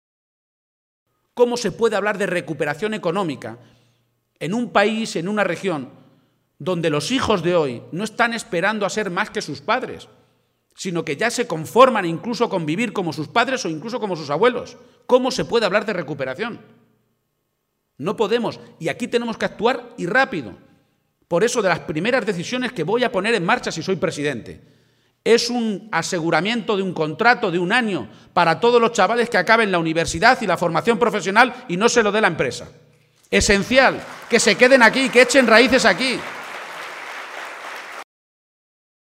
El secretario general regional y candidato del PSOE a la Presidencia de Castilla-La Mancha, Emiliano García-Page, ha aprovechado hoy un acto público en la localidad toledana de Sonseca para hacer balance de estos cuatro años de legislatura de Cospedal y ha sido muy rotundo al señalar que «hay más paro, más deuda, más soberbia, más mentira y menos sanidad, menos educación y menos población.
Audio García-Page en Sonseca 2